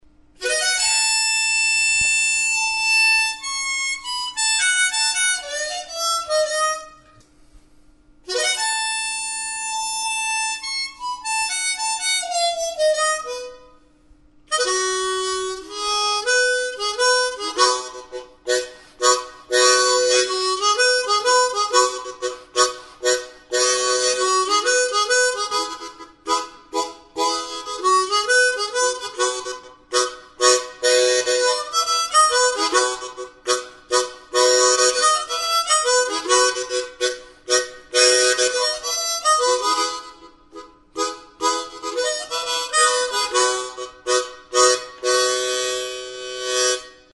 Enregistré avec cet instrument de musique.
HARMONIKA; HARMONICA; Aho-soinua
Aérophones -> Anches -> Simple Libre
Mi tonalitatean dago.